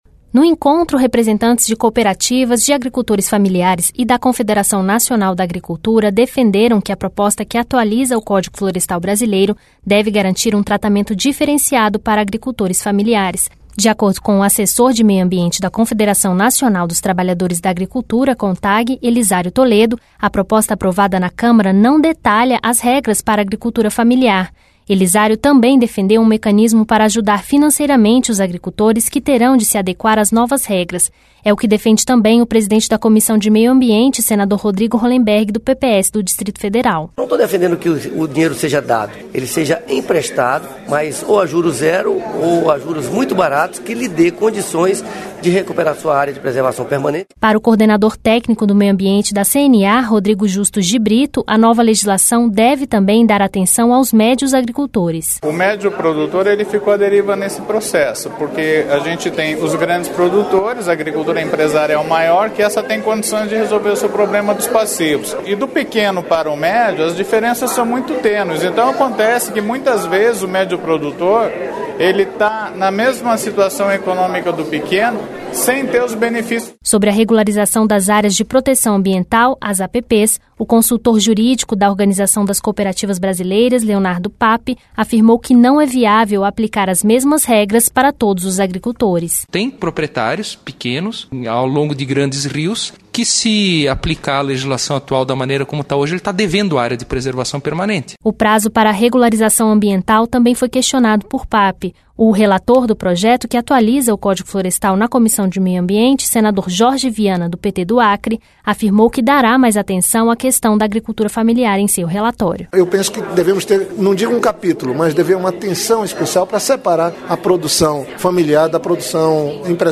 A REPÓRTAGEM